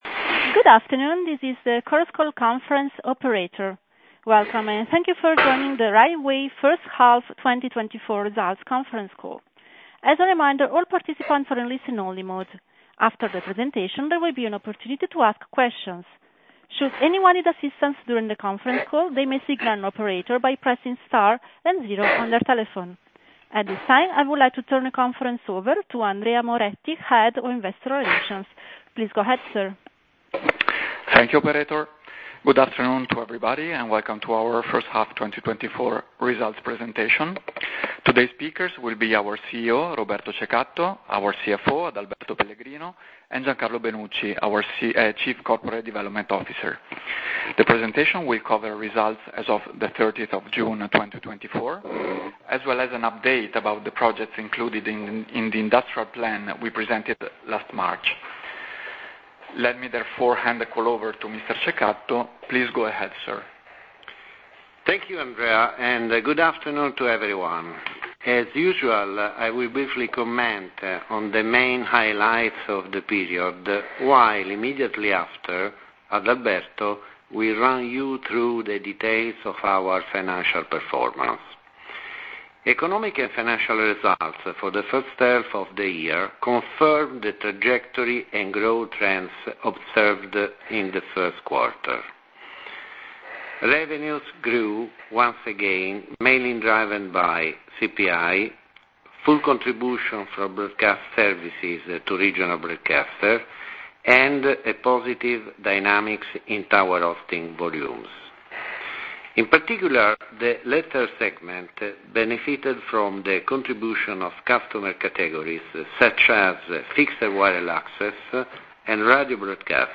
Audio mp3 Conference call Risultati 1H2024